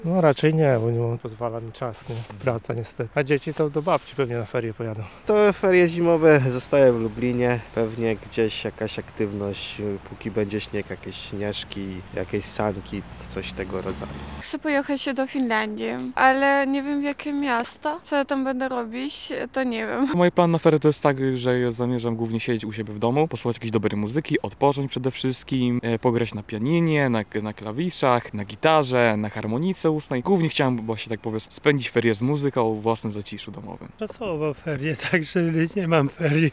W związku z tym zapytaliśmy się mieszkańców Lublina, jak zamierzają spędzić nadchodzące wolne dni:
sonda